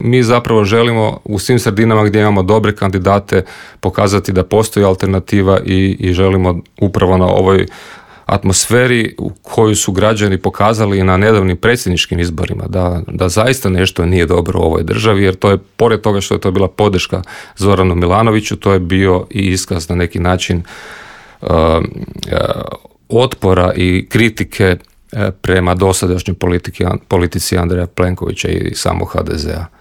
O svemu smo u Intervjuu Media servisa razgovarali sa saborskim zastupnikom SDP-a Mihaelom Zmajlovićem.